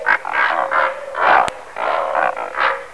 Rosapelikan (Pelecanus onocrotalus)
Stimme: Grunz-, Stöhn- und Brummlaute.
Pelecanus.onocrotalus.wav